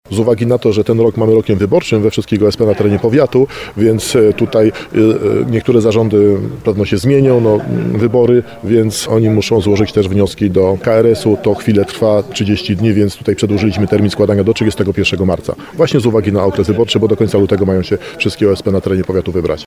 W tym roku powiat nowosądecki przedłużył termin aż do 31 marca – mówi starosta Tadeusz Zaremba.